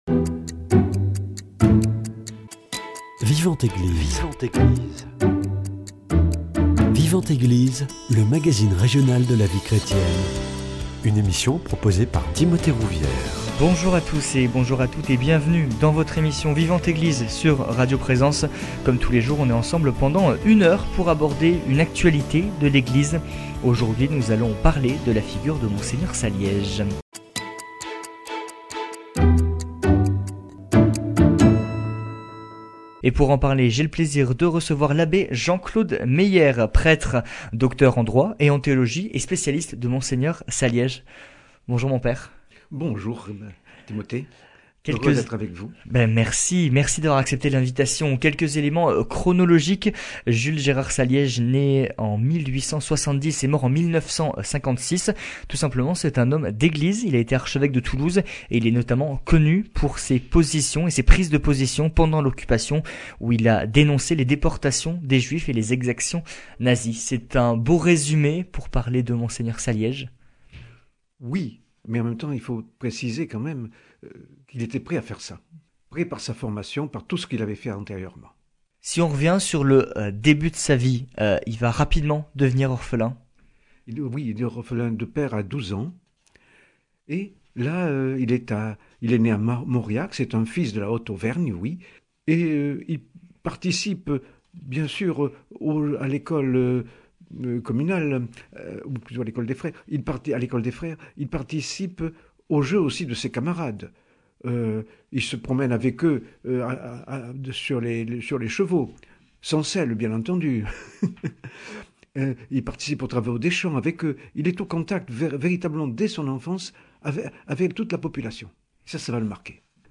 Vivante Eglise